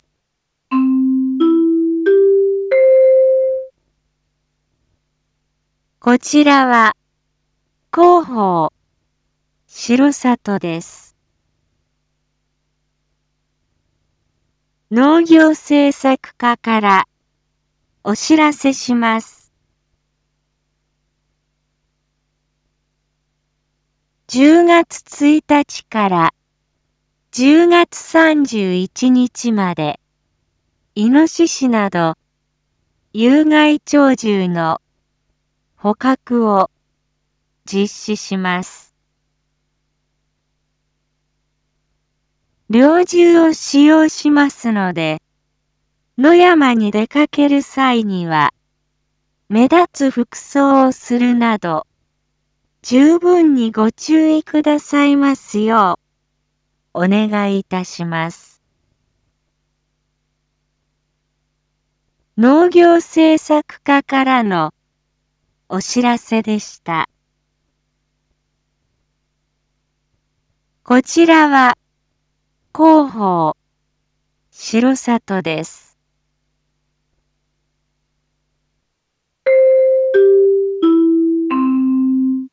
Back Home 一般放送情報 音声放送 再生 一般放送情報 登録日時：2023-10-20 19:01:24 タイトル：有害鳥獣捕獲について インフォメーション：こちらは、広報しろさとです。